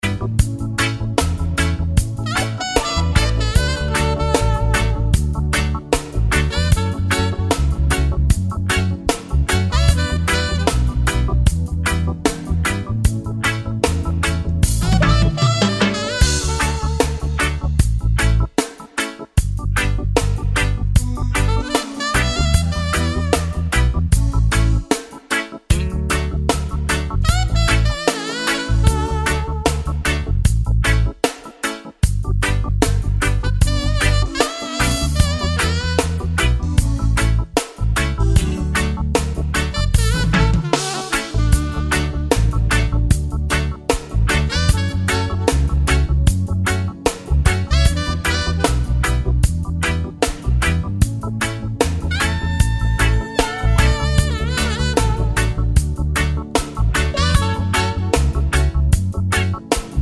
no Backing Vocals Reggae 4:11 Buy £1.50